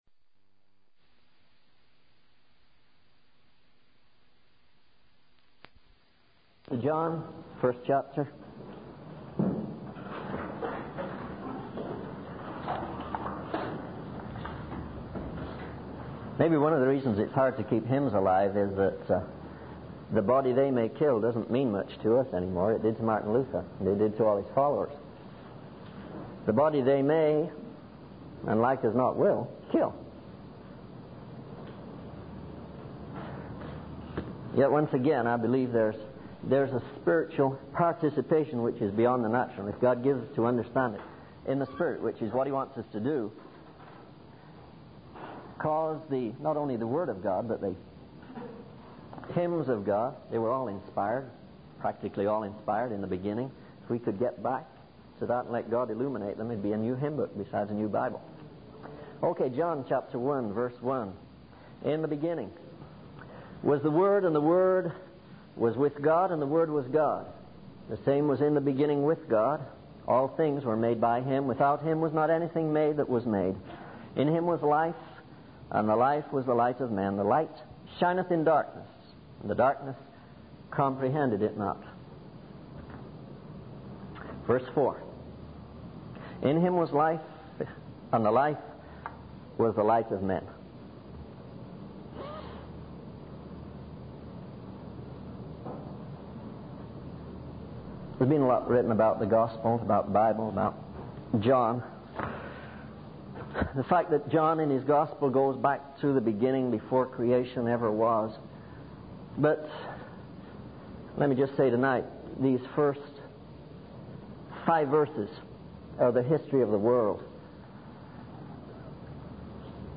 In this sermon, the speaker emphasizes that coming to God and learning about Him is not achieved through processes or steps. He shares the story of a simple missionary who, without any formal education, felt called to the mission field and prayed for revival.